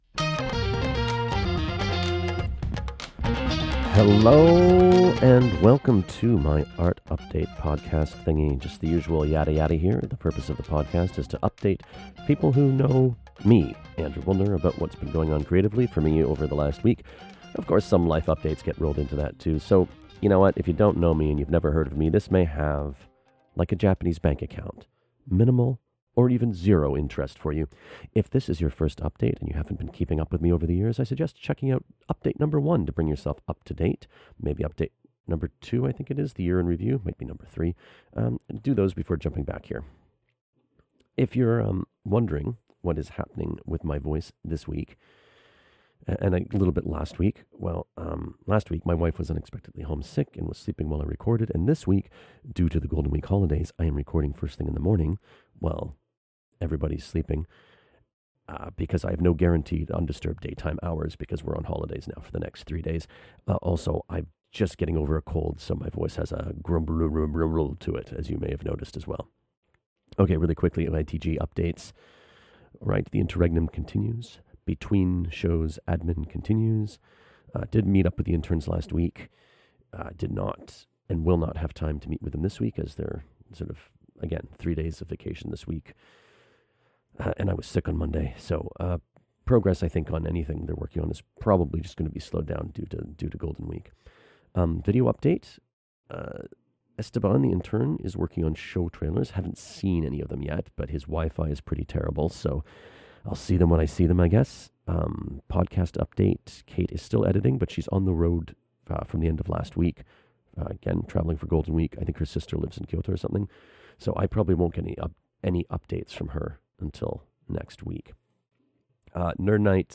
My voice is soooo rough this week.